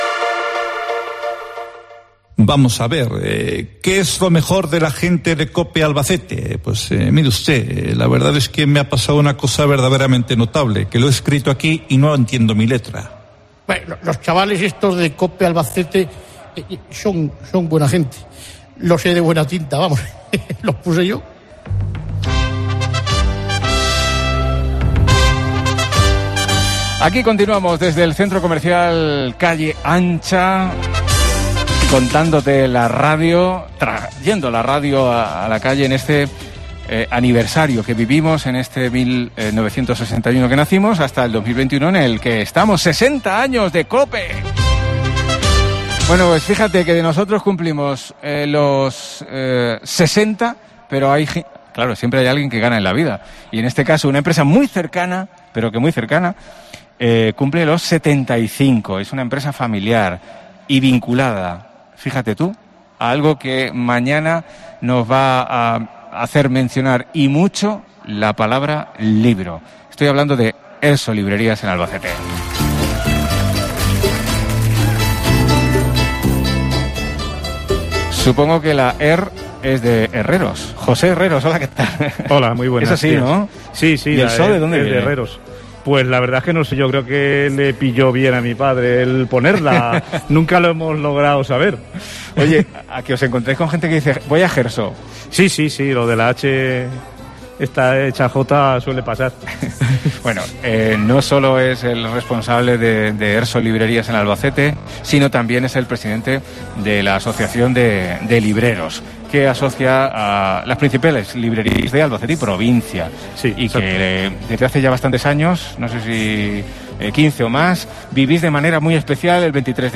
Desde el Centro Comercial Calle Ancha